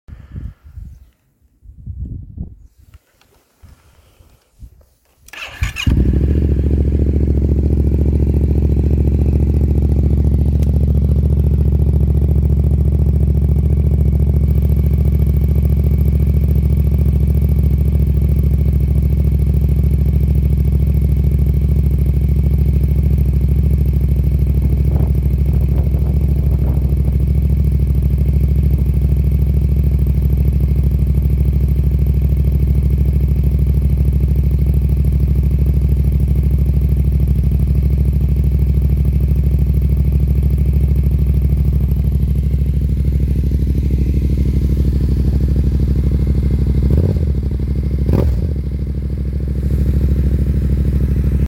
Yamaha Mt07 Cold Start Sc Sound Effects Free Download